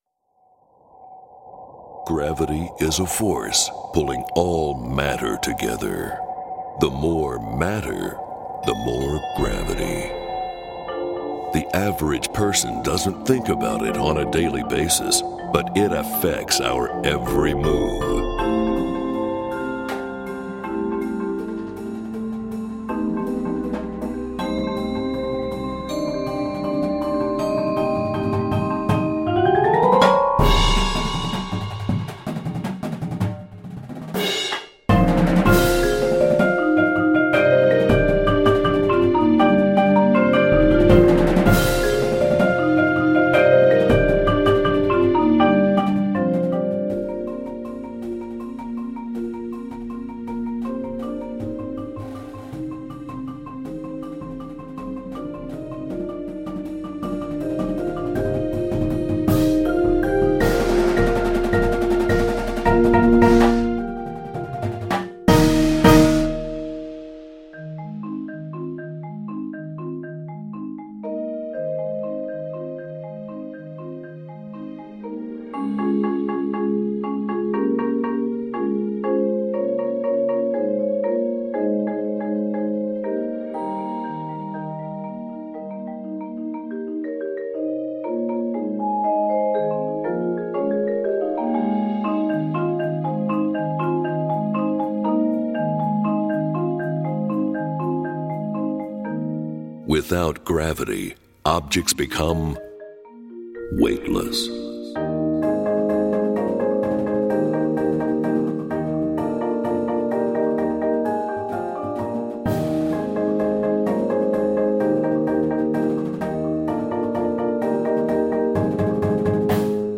space themed Indoor Percussion Show